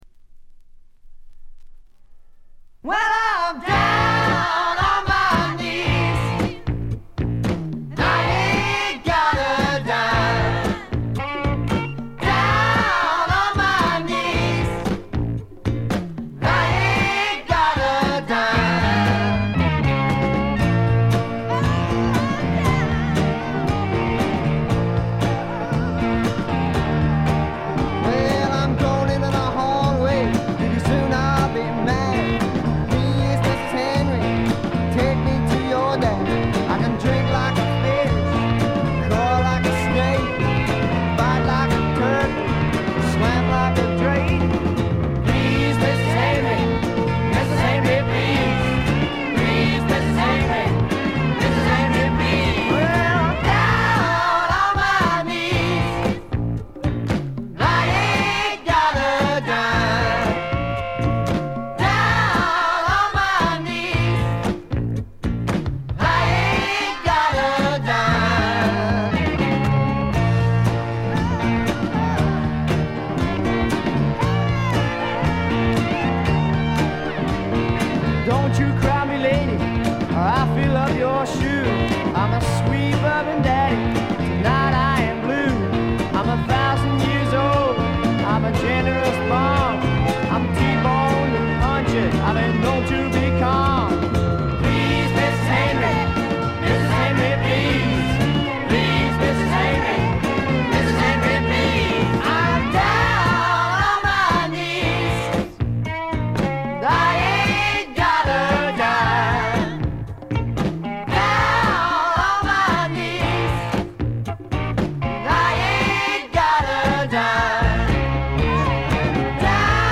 試聴曲は現品からの取り込み音源です。
Recorded at Maximum Sound, I.B.C. Studios